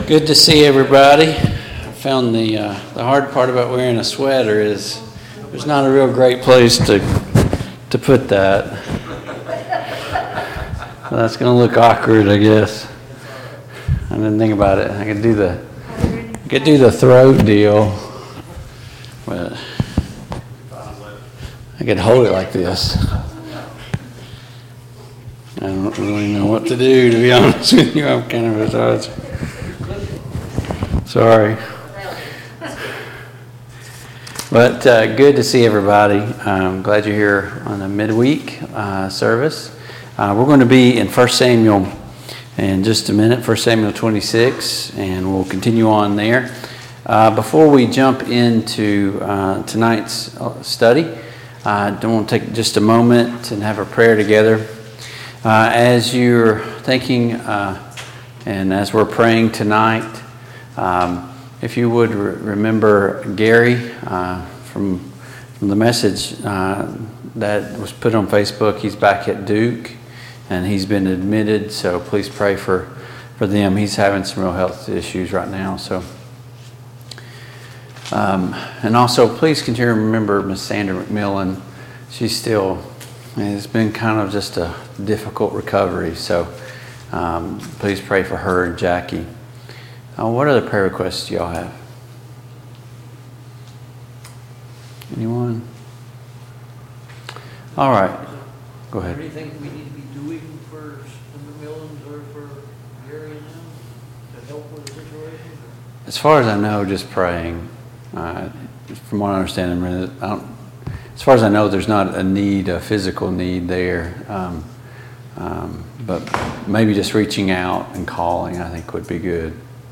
The Kings of Israel Passage: I Samuel 26-28 Service Type: Mid-Week Bible Study Download Files Notes « Keys to being a Successful Evangelist 10.